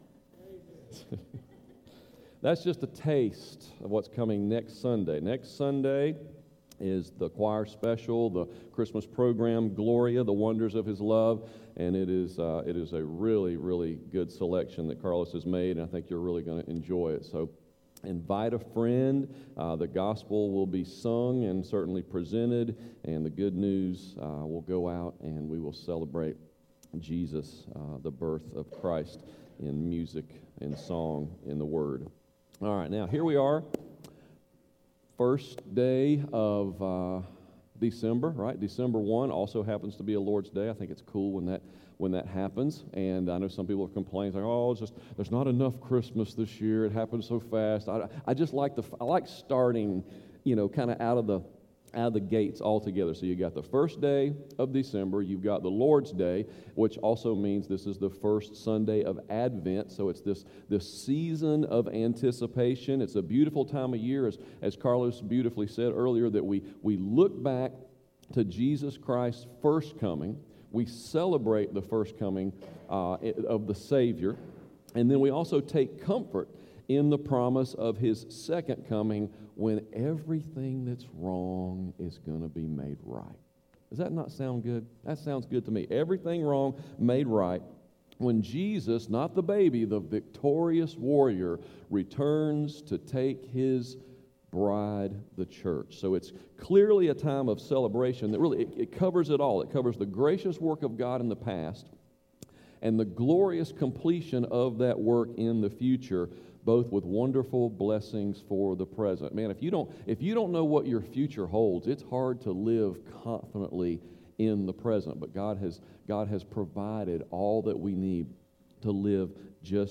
Sermons | Hampton Heights Baptist Church